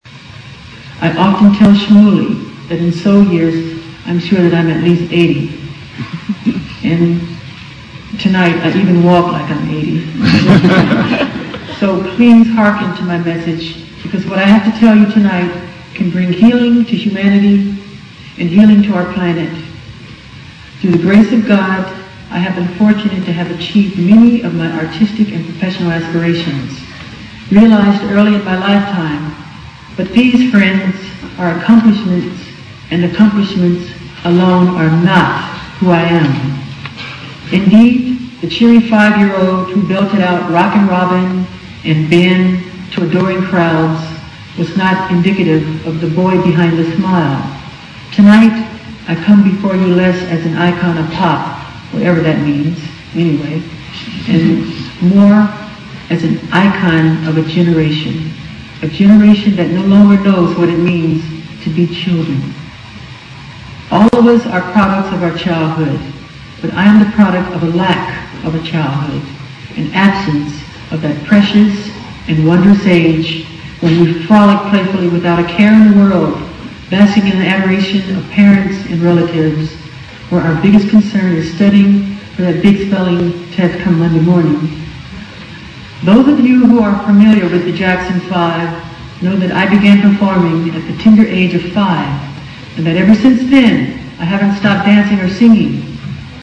名校励志英语演讲 49:拯救世界 拯救儿童 听力文件下载—在线英语听力室
借音频听演讲，感受现场的气氛，聆听名人之声，感悟世界级人物送给大学毕业生的成功忠告。